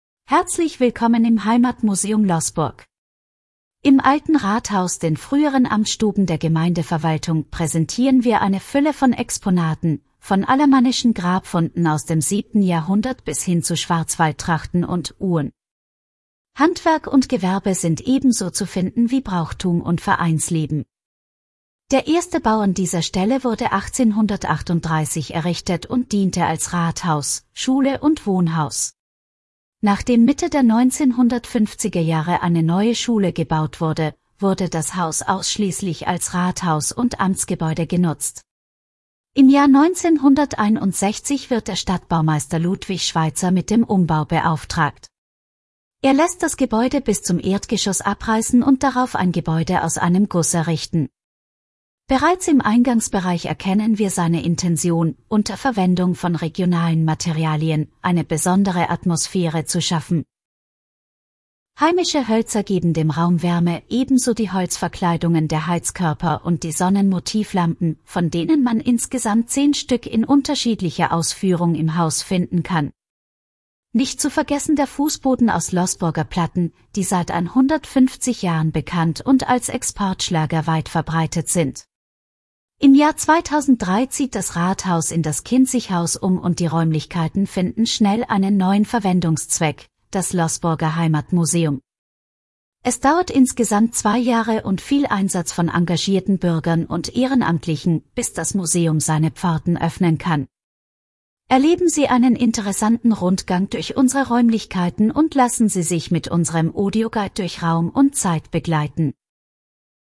Audioguide Heimatmuseum Loßburg